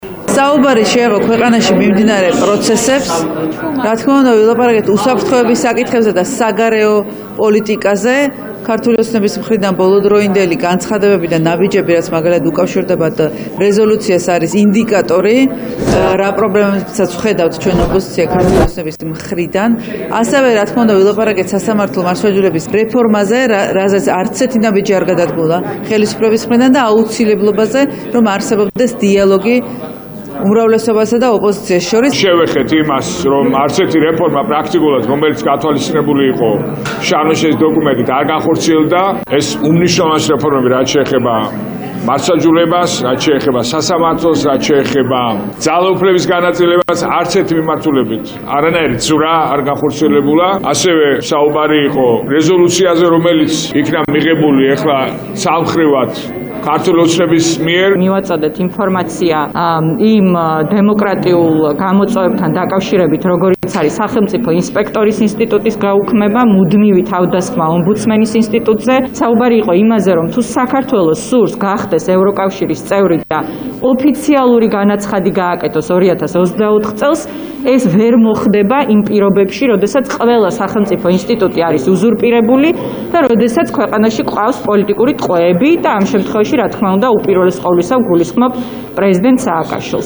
ხათუნა სამნიძის ბადრი ჯაფარიძის თინა ბოკუჩავას ხმა